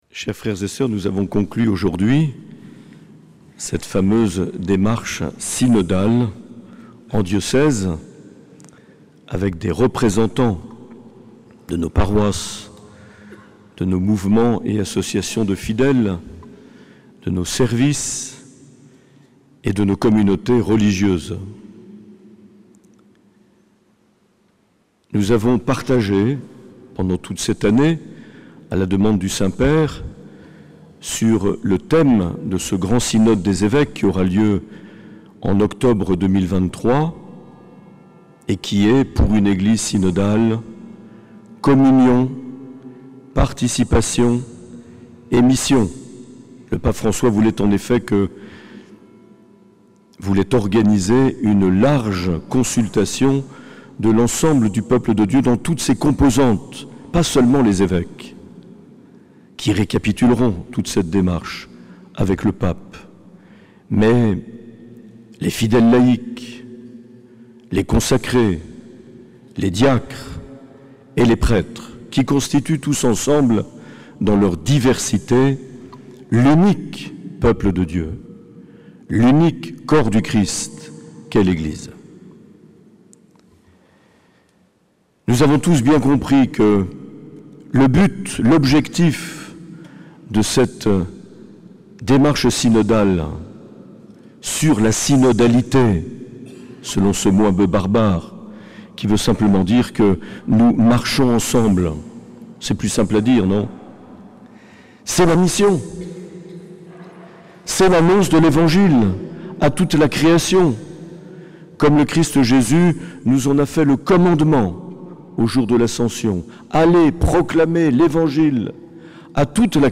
4 juin 2022 - Cathédrale de Bayonne - Vigile de Pentecôte
Homélie de Mgr Marc Aillet.